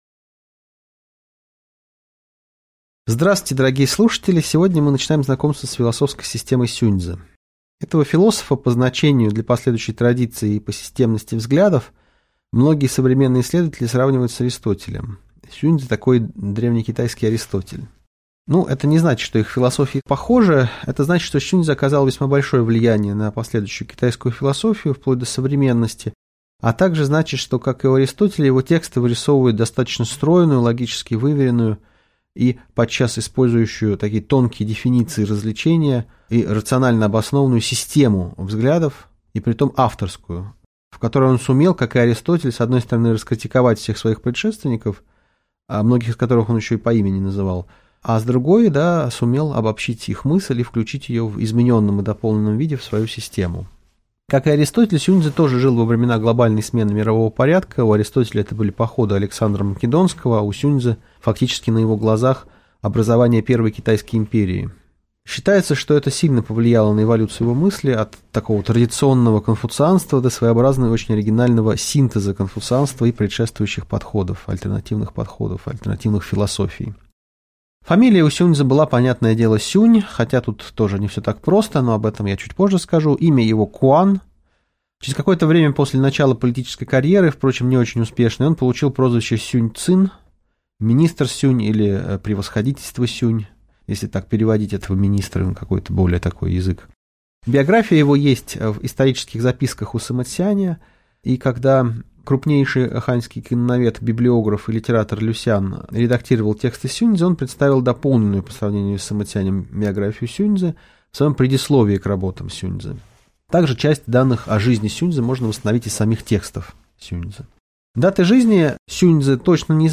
Аудиокнига Лекция «Сюнь-цзы. Часть I» | Библиотека аудиокниг